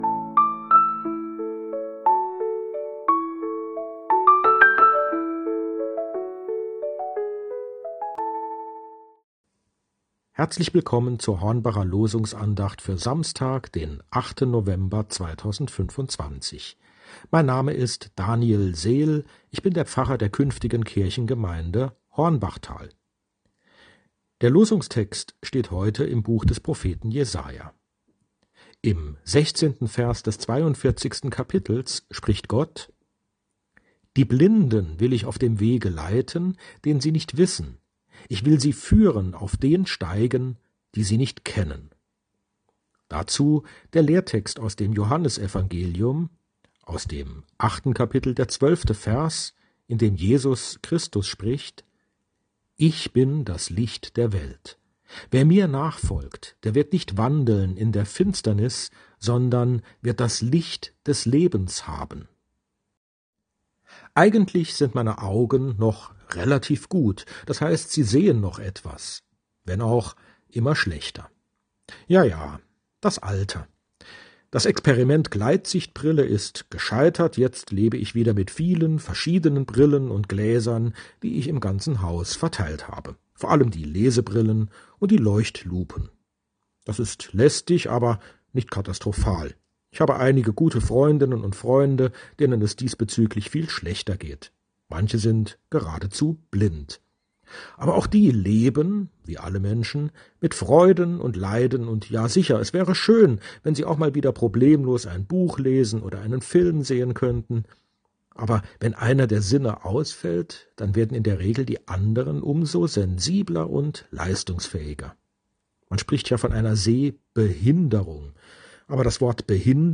Losungsandacht für Samstag, 08.11.2025